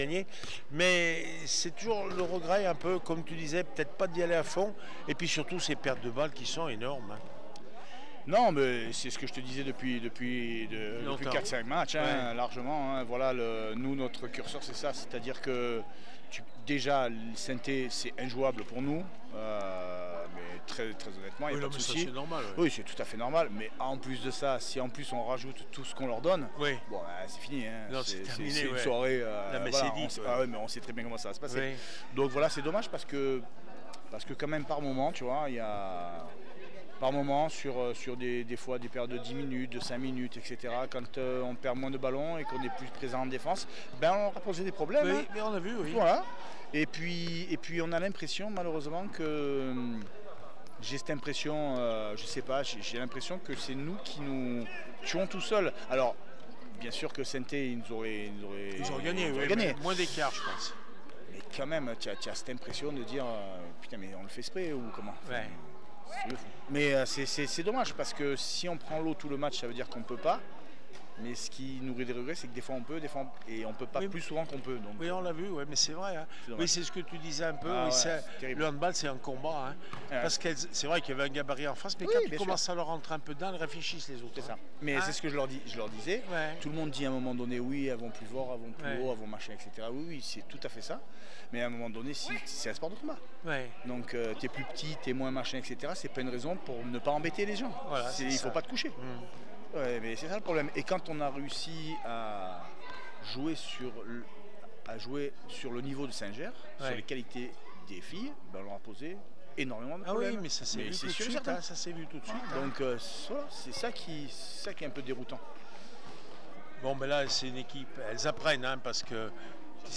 7 avril 2025   1 - Sport, 1 - Vos interviews